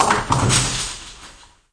bowling-2.wav